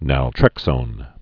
(năl-trĕksōn)